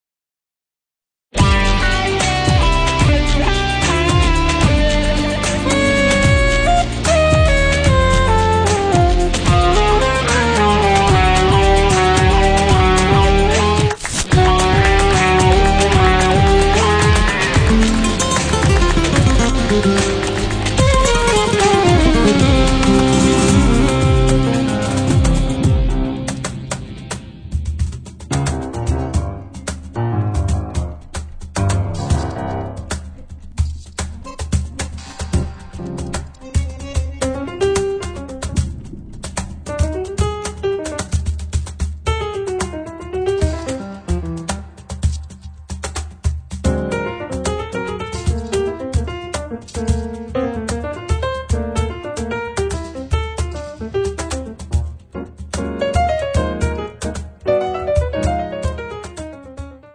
piano
basso elettrico
bandoneon, electronics